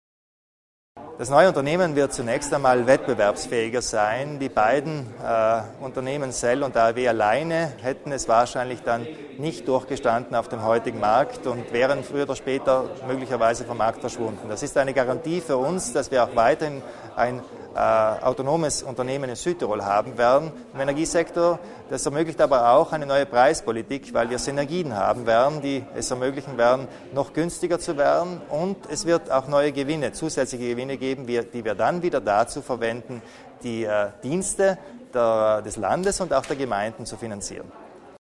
Landeshauptmann Kompatscher über die Vorteile der Fusion von SEL und Etschwerke